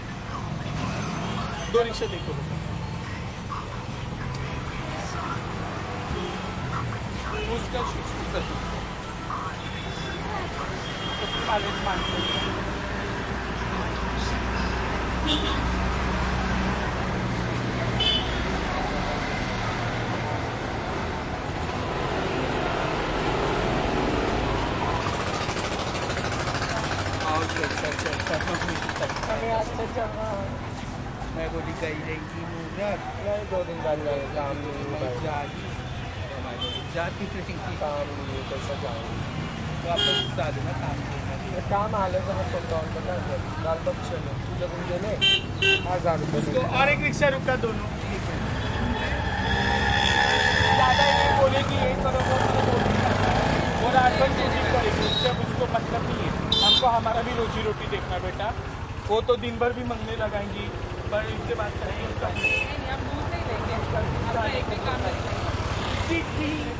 印度 " 交通繁忙的街道 深沉的音调 喉音的喇叭声 短暂的印度
描述：交通繁忙的街道深沉的口气喉咙喇叭鸣响短的India.flac
Tag: 交通 街道 喇叭 印度 honks 沙哑